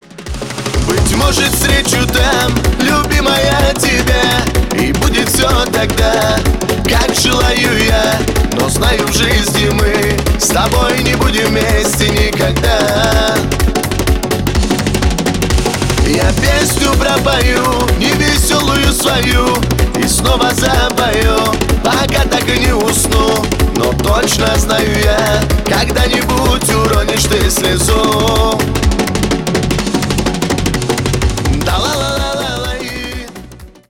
Шансон
кавказские